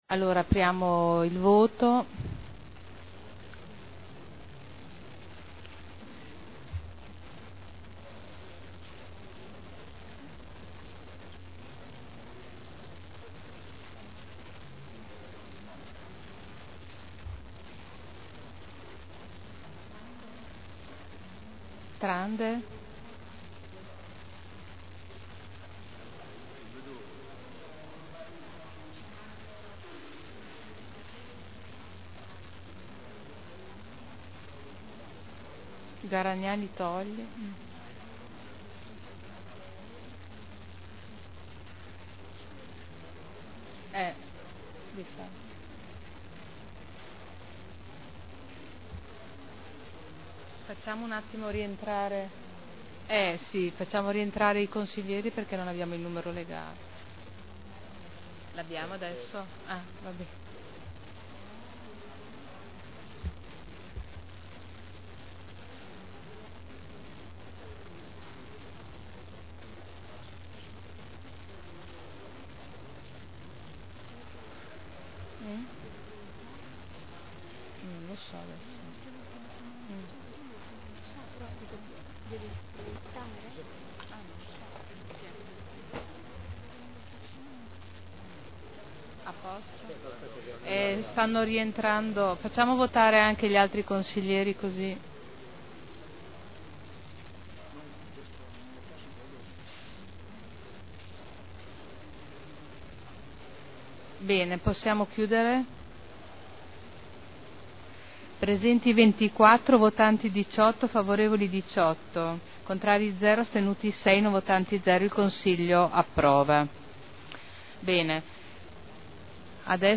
Seduta del 10/12/2009. Mette in votazione: Nulla osta al rilascio di permesso di costruire in deroga agli strumenti urbanistici (Art. 31.23 RUE) – Richiesta di permesso di costruire 1163/2009 presentata dalla Pomposiana Cooperativa Sociale a responsabilità limitata per ampliamento ad uso deposito attrezzature agricole